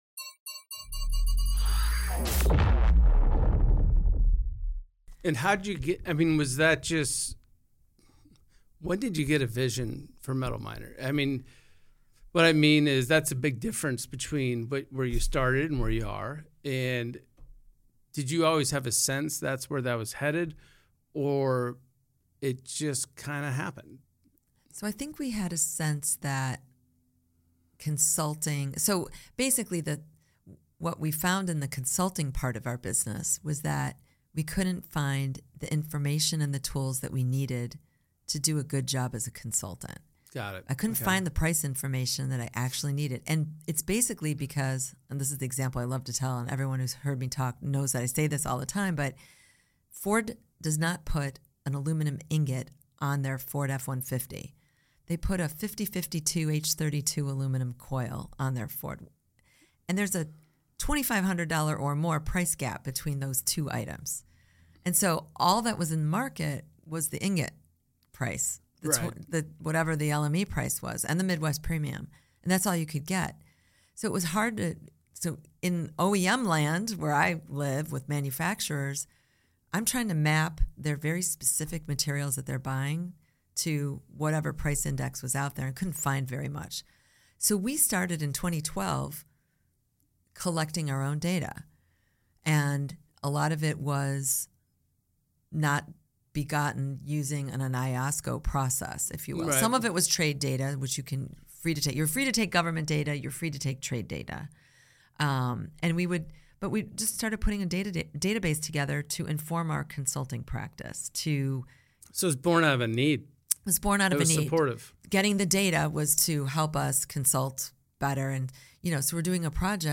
A thought-provoking conversation about data, technology, and the human side of innovation in metals.